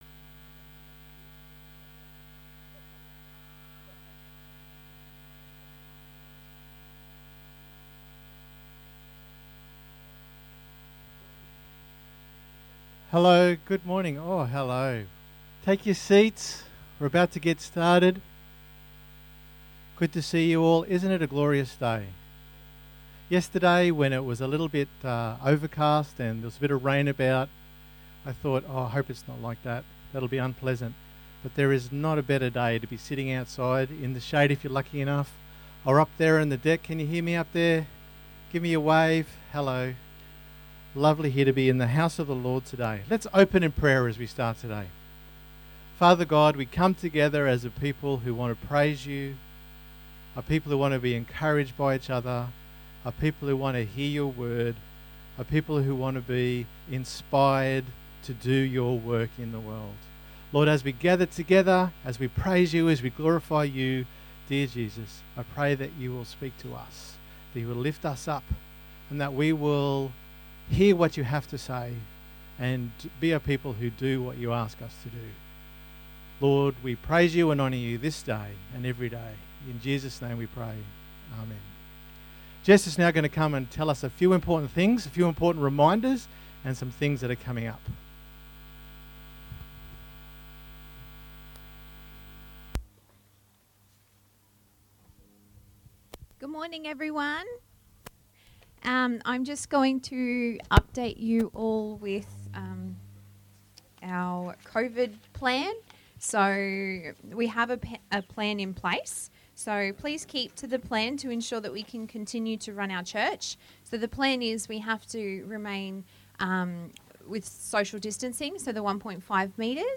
The reading is from Mathew 14:13-21 This is the whole service, enjoy and God Bless you as you listen '